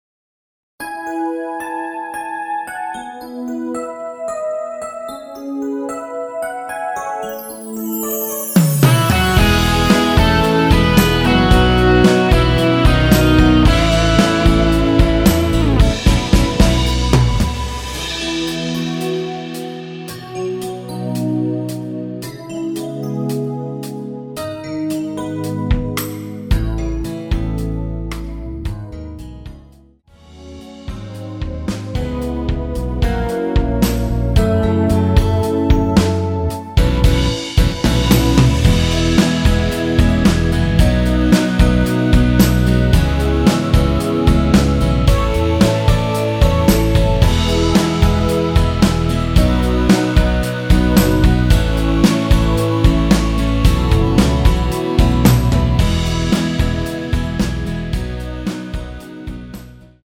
음정은 반음정씩 변하게 되며 노래방도 마찬가지로 반음정씩 변하게 됩니다.
앞부분30초, 뒷부분30초씩 편집해서 올려 드리고 있습니다.
중간에 음이 끈어지고 다시 나오는 이유는